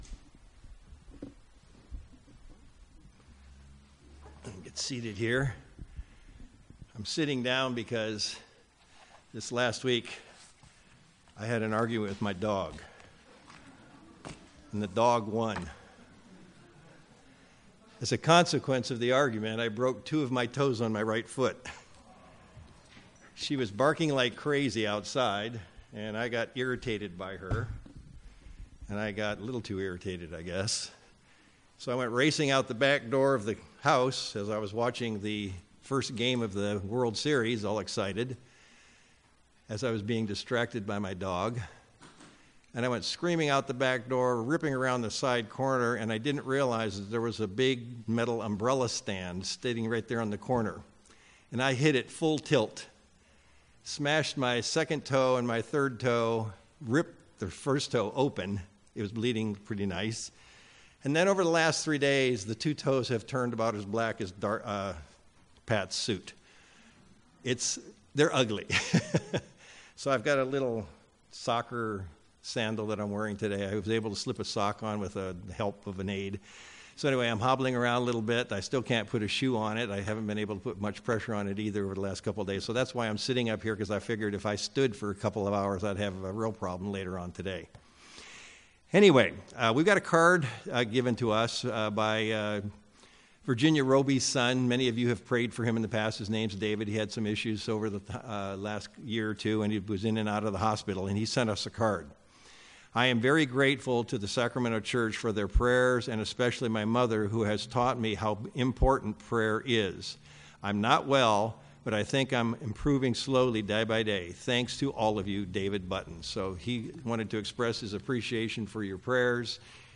View on YouTube UCG Sermon Studying the bible?